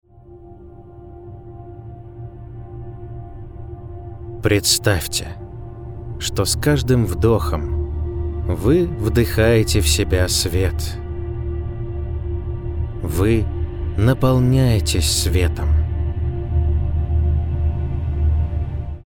Муж, Другая/Зрелый
Дикторская кабина, Lewitt LCT440 PURE, Audient iD4 MKII.